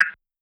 normal-hitfinish-1.wav